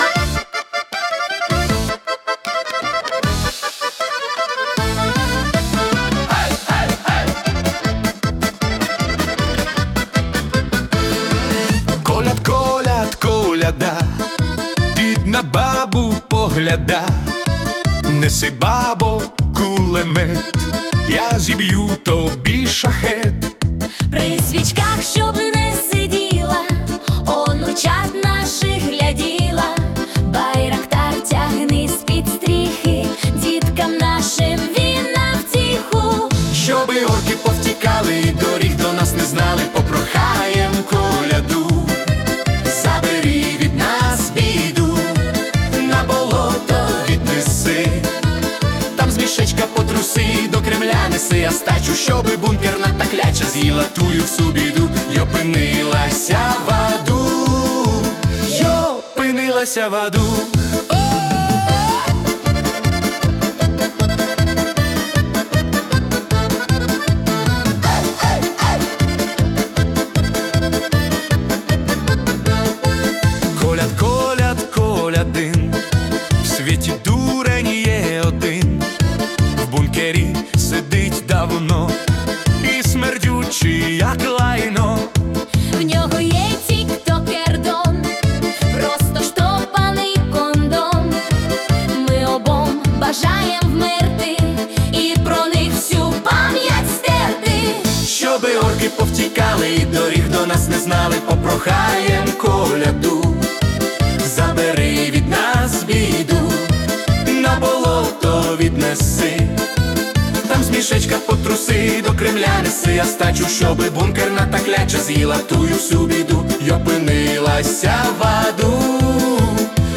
🎵 Жанр: Сатирична полька